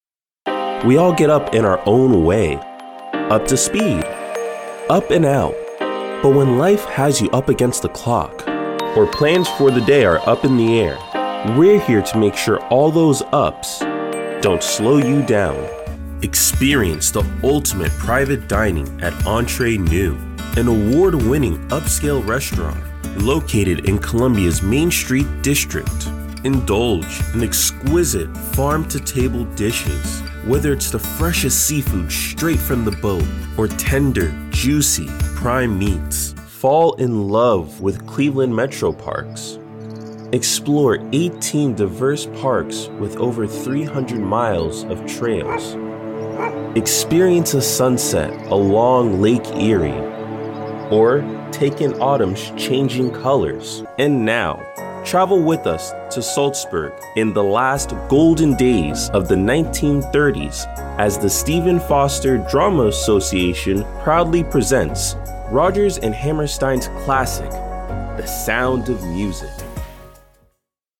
Friendly and energetic individual that brings life to all narrations!
Young Adult